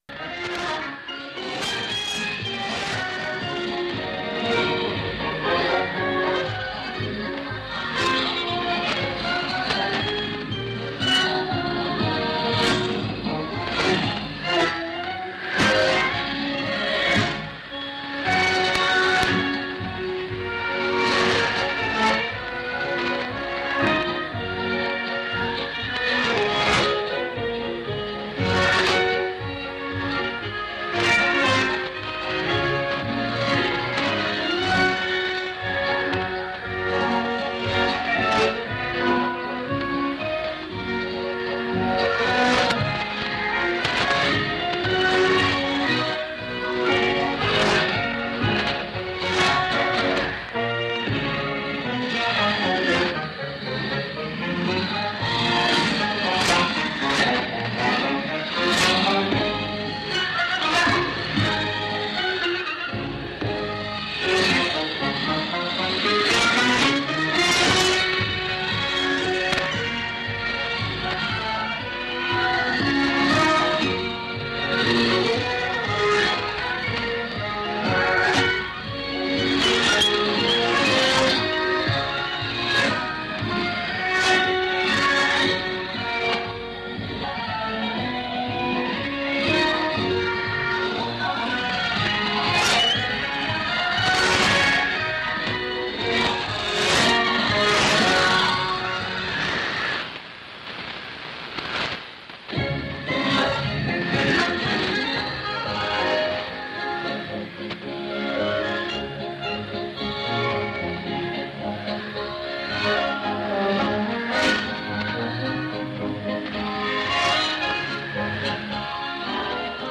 Radioescucha: WWCR, 40 minutos de música de banda, pero de banda militar
Frecuencia: 6115 khz Hora: 5:20 p.m. local y 00:20 Receptor Kenwood R-600 Antena de hilo largo, 10 metros de longitud y 6 metros de alto.